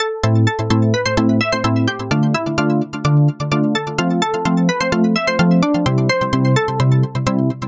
和弦与旋律的弹奏
描述：和弦和旋律的拨动。
标签： 128 bpm House Loops Synth Loops 1.29 MB wav Key : A
声道立体声